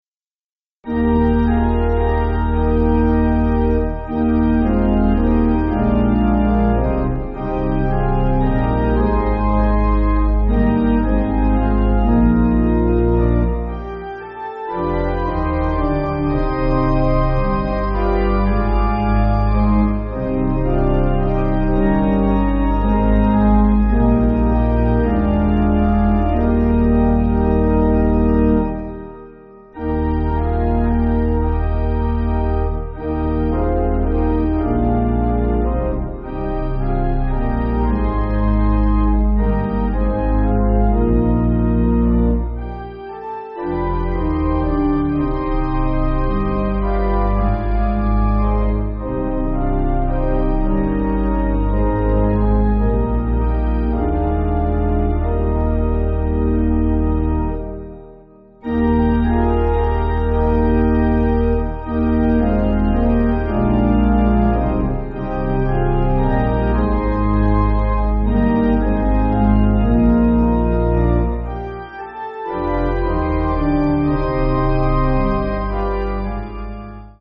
(CM)   4/Eb